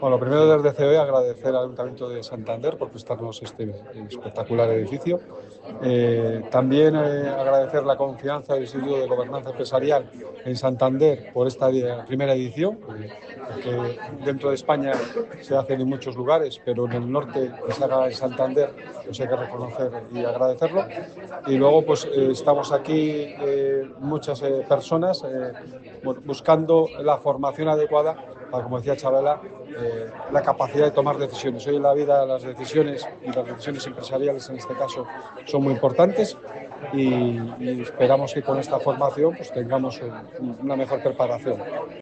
El acto se ha desarrollado en las instalaciones del Centro de Iniciativas Empresariales (CIE) del ayuntamiento de Santander que acoge estas primeras sesiones del Programa como una forma de reforzar el reciente acuerdo de colaboración entre ambas entidades (Ayuntamiento y CEOE) para fomentar la formación directiva y el emprendimiento empresarial.